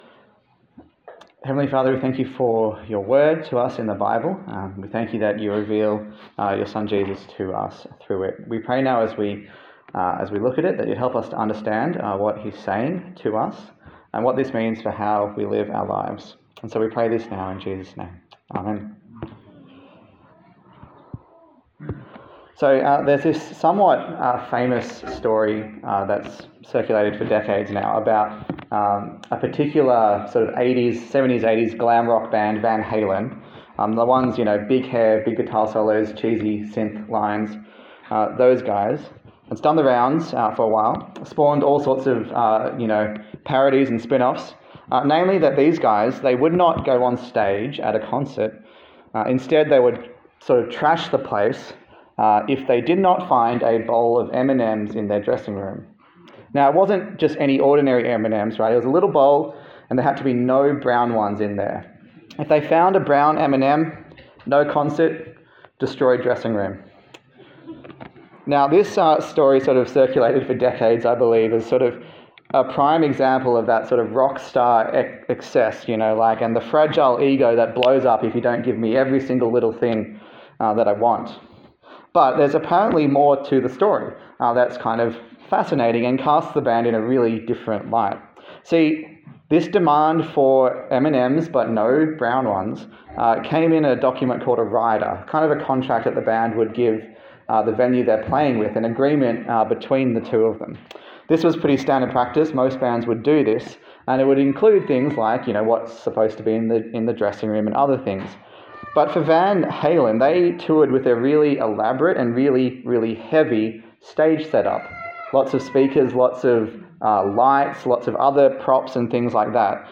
Service Type: Sunday Service A sermon in the series on the Gospel of Luke